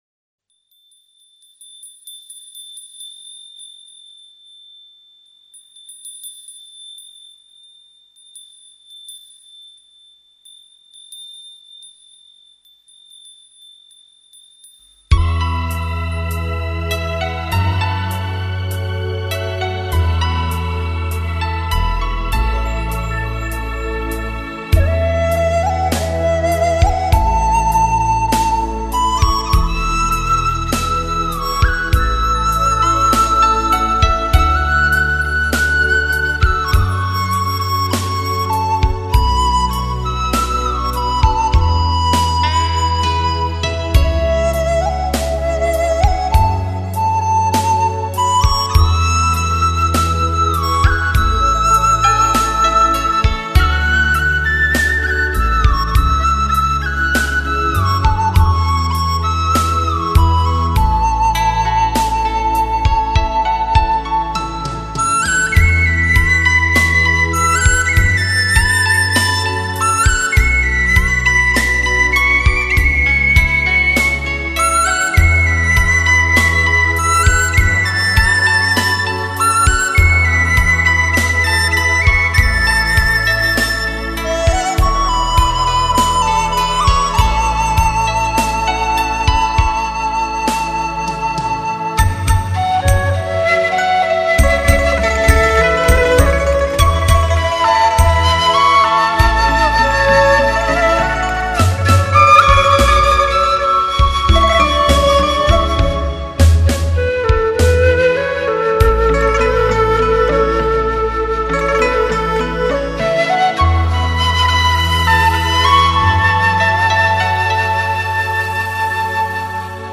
透过传统器乐与中国新世纪乐风的诠演
现代版竹林七贤式的音乐风格，深刻满足现代人追求安宁生活的需求，恬淡空灵、意象无限，带来无尽的内在共鸣 ！
简单自然的旋律，谱出属于陶渊明的田园生活，让 您在音乐里眺望原野、亲近自然，彷佛悠游在桃花源中 ，感受恬淡自如的生活！